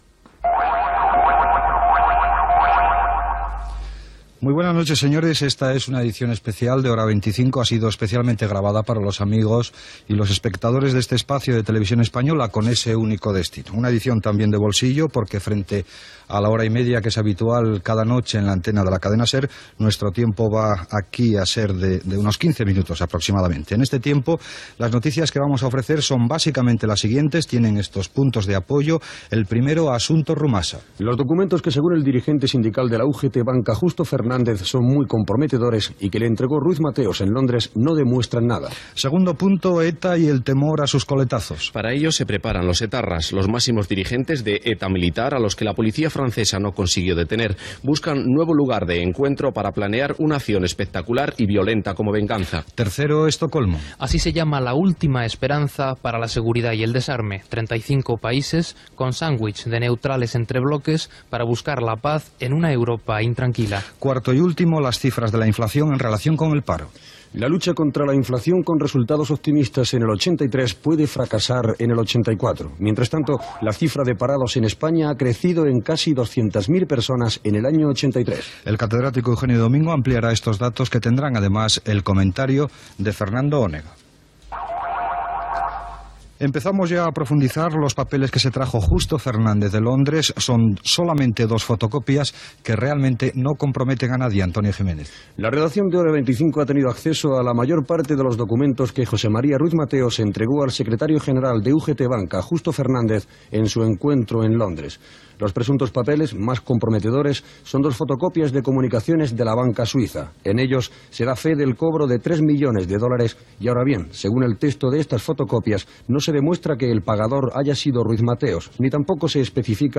e113c792dbff19961d0f875a78f64bd6d0bcd403.mp3 Títol Cadena SER Emissora Televisión Española Cadena SER Titularitat Privada estatal Nom programa Hora 25 Descripció Edició especial feta a TVE.
Gènere radiofònic Informatiu